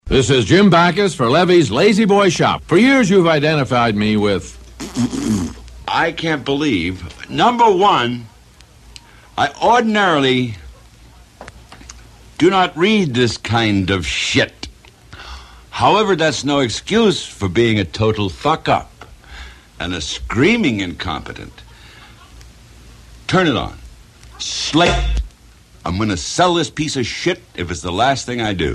Tags: Celebrities Bloopers Barry White Celebrity bloopers Blooper Audio clips